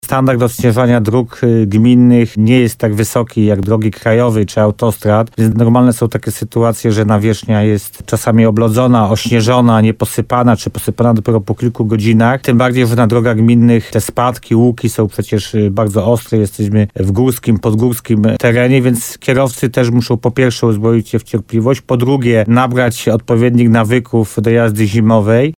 - Zanim drogi po opadach śniegu będą czarne trzeba poczekać - mówi Paweł Ptaszek, wójt gminy Tymbark.
Gość radia RDN Nowy Sącz w programie Słowo za Słowo podkreślał, że region limanowski jest specyficzny pod kątem odśnieżania.